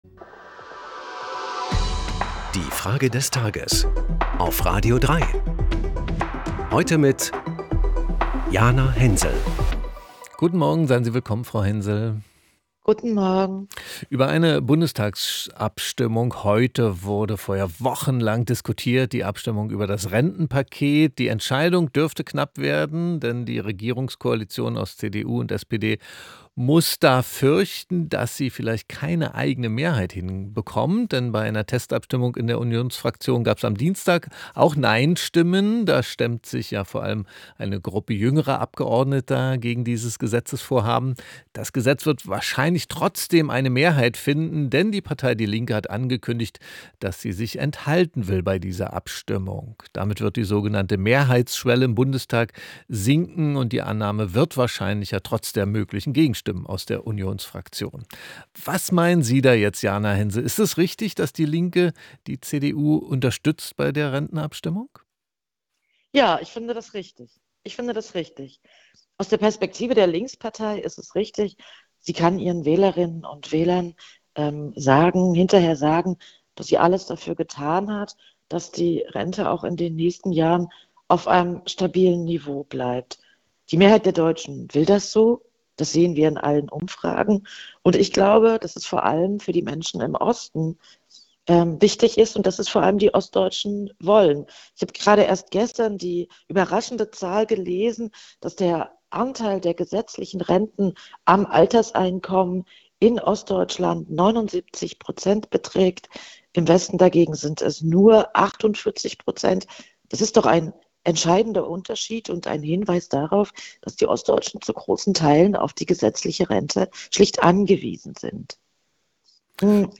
Wir fragen die Journalistin und Autorin Jana Hensel in